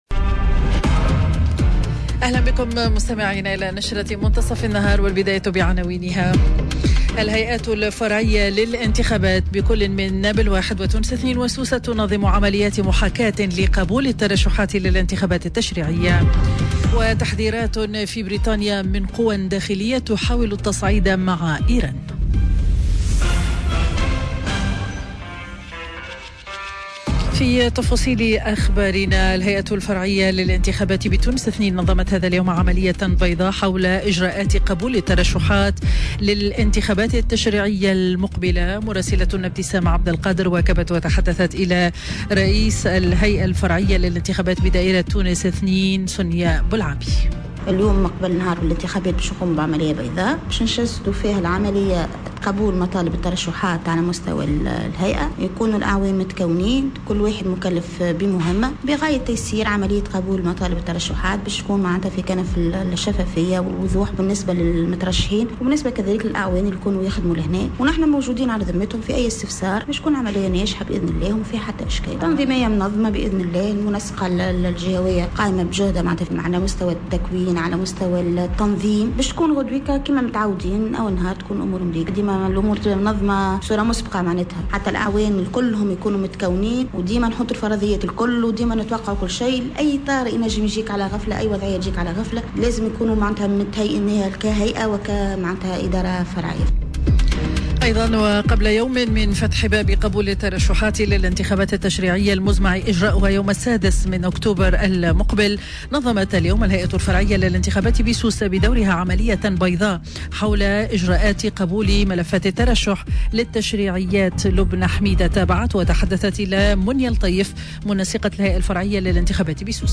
نشرة أخبار منتصف النهار ليوم الأحد 21 جويلية 2019